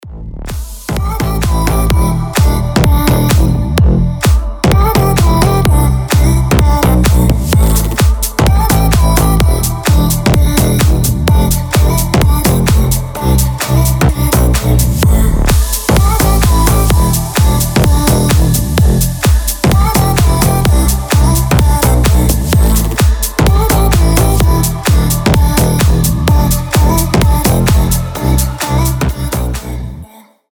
• Качество: 320, Stereo
громкие
EDM
мощные басы
future house
энергичные
slap house
Громкий энергичный звонок